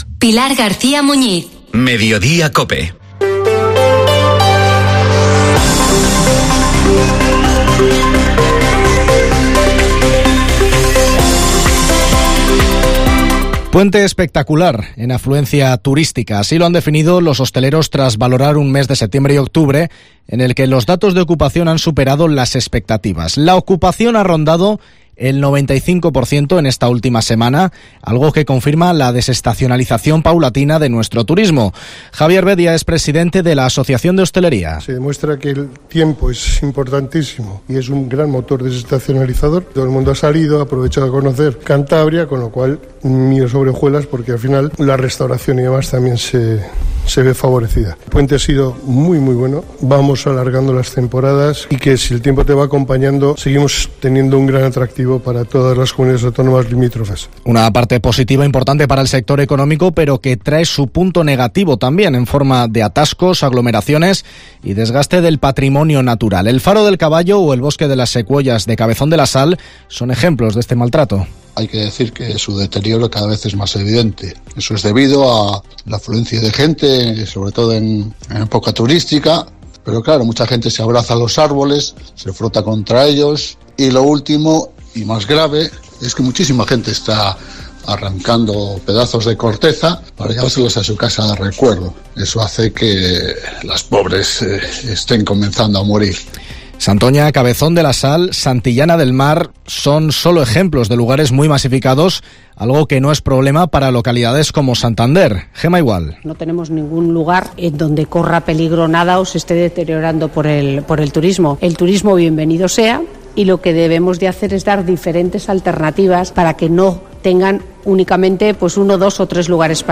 Informativo MEDIODIA en COPE CANTABRIA 14:48